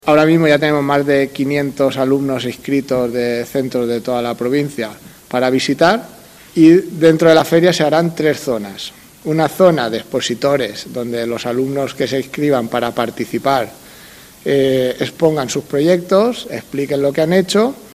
A mes y medio de su celebración ya ha comenzado a despertar el interés entre los participantes, como ha explicado en rueda de prensa uno de los profesores organizadores